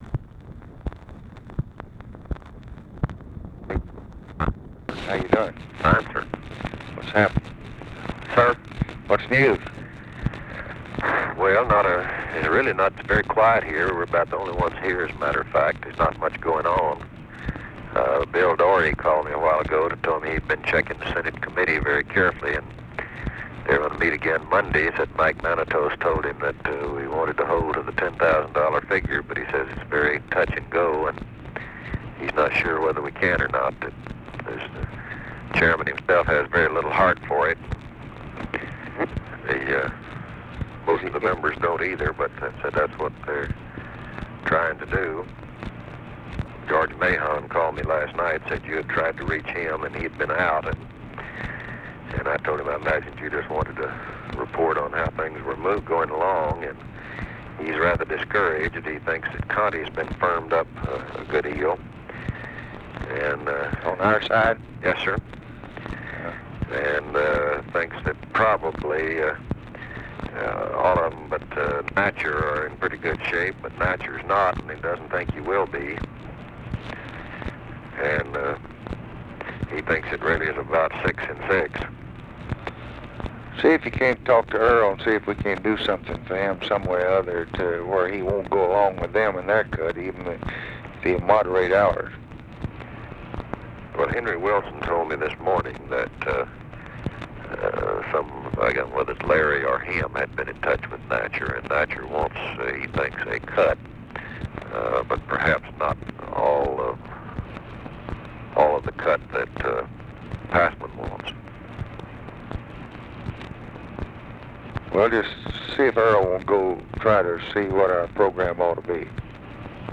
Conversation with WALTER JENKINS, June 20, 1964
Secret White House Tapes